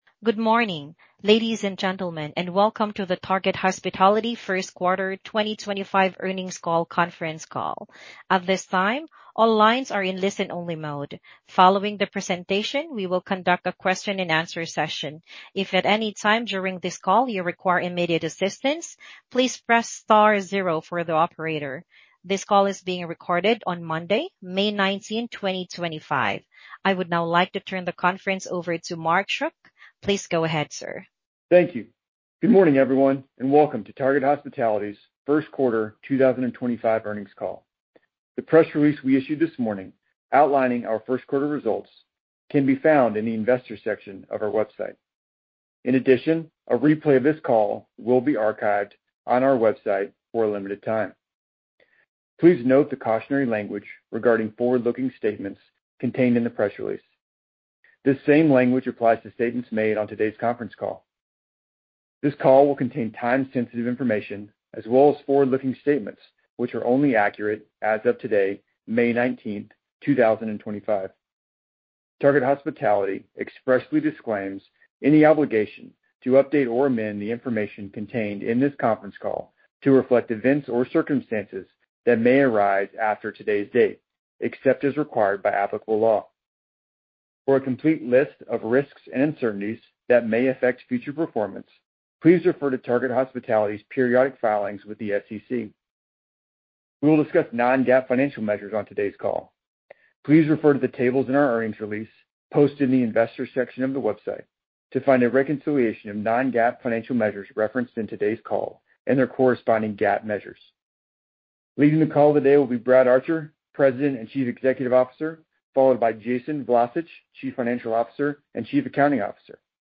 First Quarter 2025 Earnings Call
TH-Q1-2025-Earnings-Call.mp3